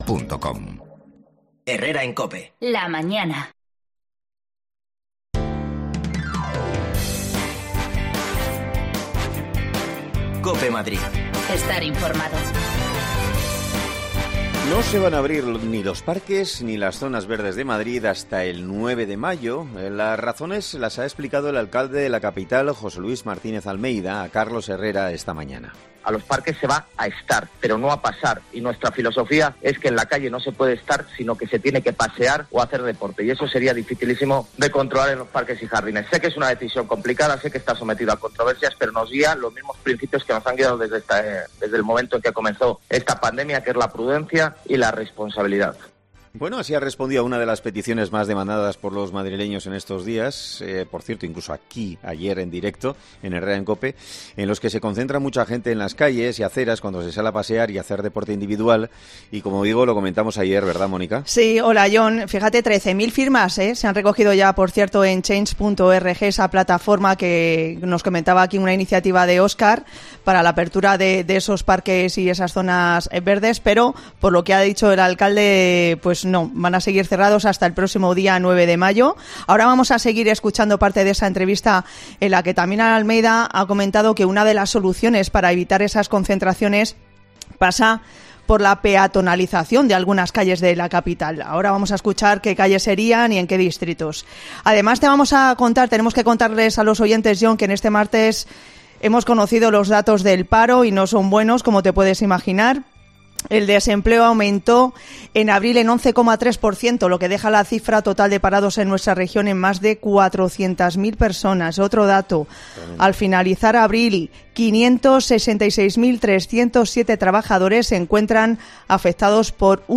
AUDIO: Los parques no se abrián, por precaución, hasta el 9 de mayo. Lo ha dicho en Cope el alcalde de Madrid en una entrevista con Carlos Herrera